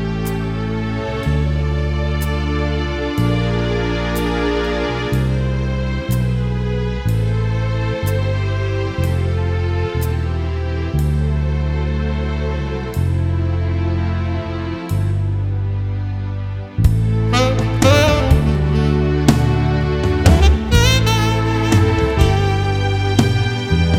no piano Version 1 Pop (1980s) 5:07 Buy £1.50